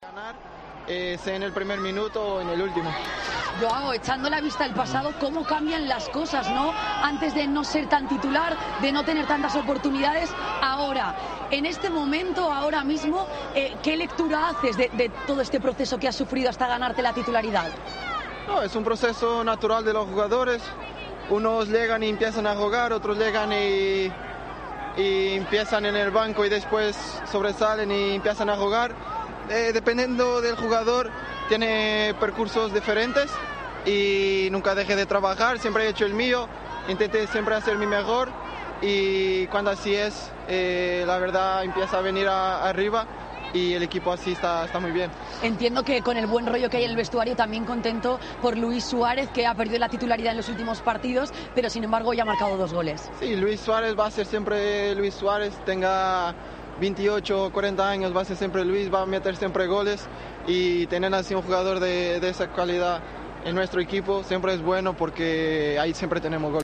El delantero rojiblanco ha hablado en Movistar+ del momento que ha vivido en el Atlético de Madrid: de tener pocas oportunidades a ganarse la titularidad.